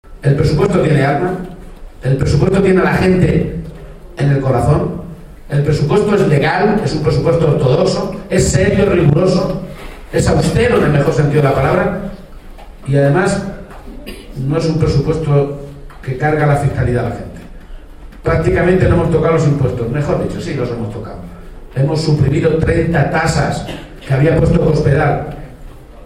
Durante un encuentro con militantes y simpatizantes del PSOE, celebrado en el Mesón Casa Antonio de Tobarra (Albacete)
Cortes de audio de la rueda de prensa